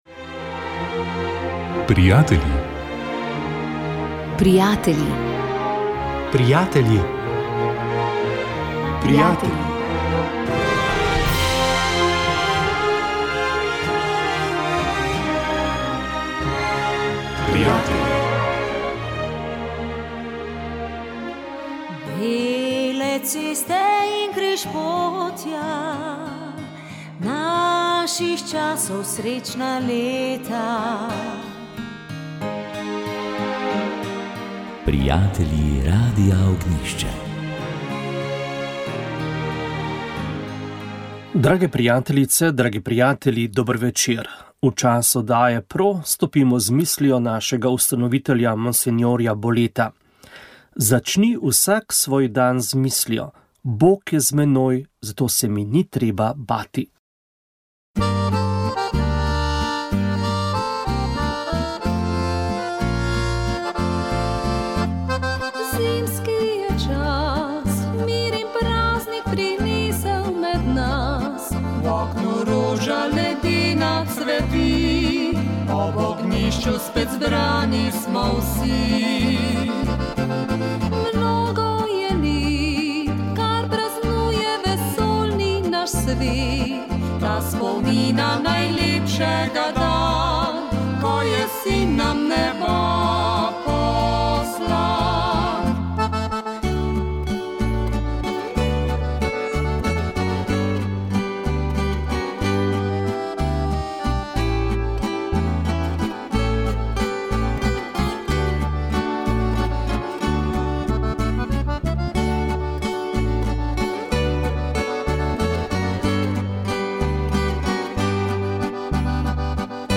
V večerni božični oddaji PRO smo prebirali pismo papeža Frančiška o pomenu jaslic, ki so nedvomno najlepši in najboljši okras božičnega praznovanja. Med odlomki pisma smo predvajali izbrane božične pesmi, ki opevajo skrivnost Jezusovega rojstva.